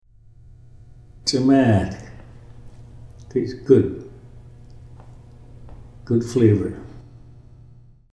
The usual way it to butterfly, flour and cook them in a shallow fry (mmmm ts’maatk! [ts’m/*aatk] – good tasting; tasty; sweet tasting)